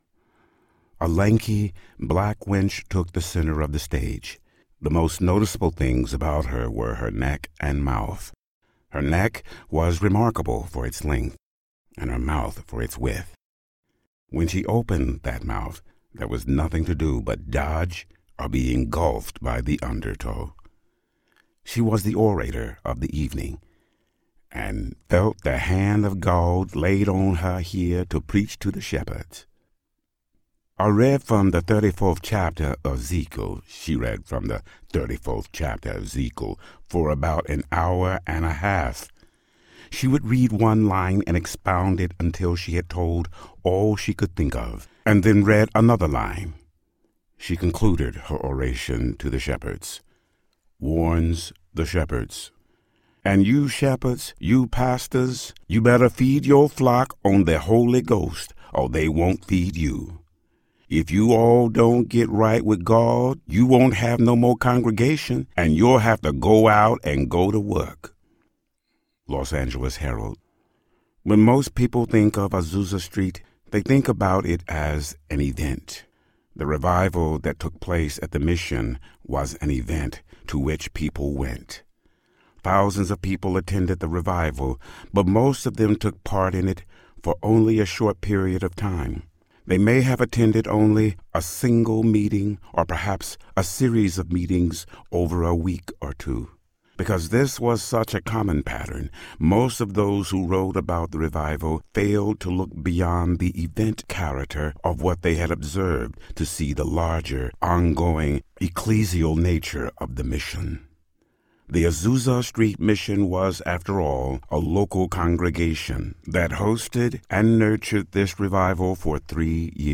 The Azusa Street Mission and Revival Audiobook
Narrator